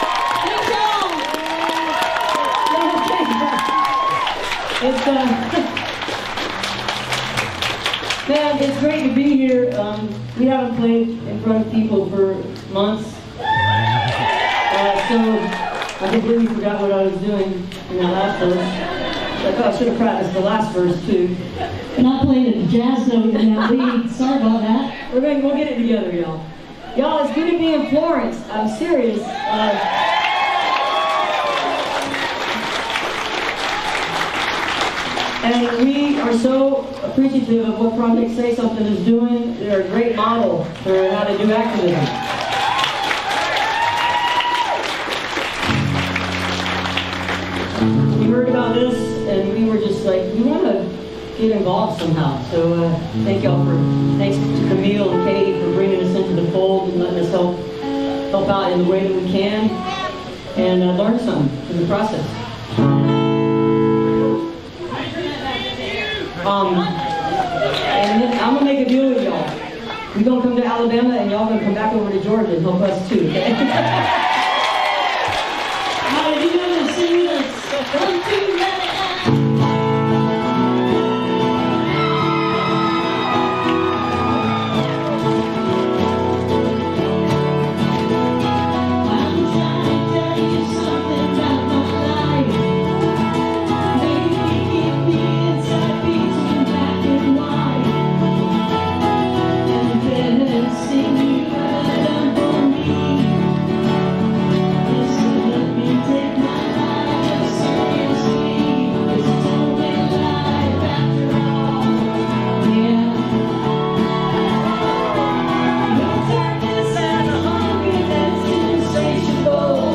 (captured from youtube)